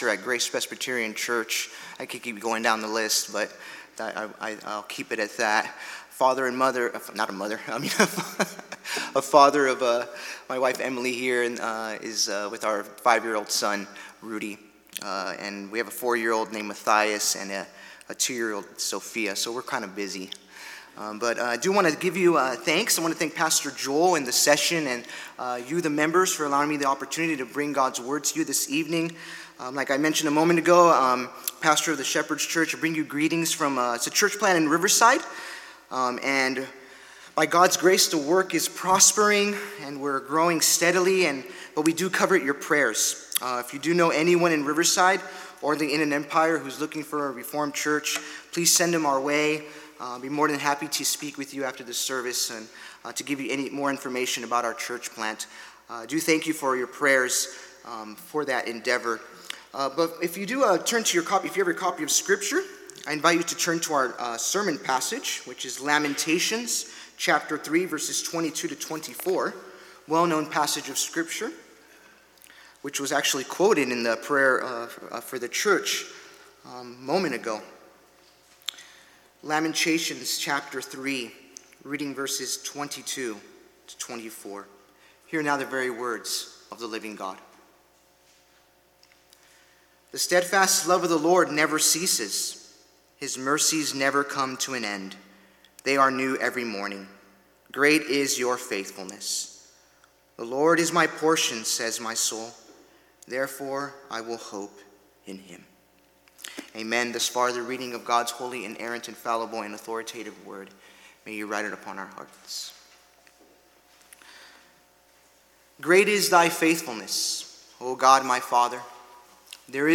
Sermons | New Life Presbyterian Church of La Mesa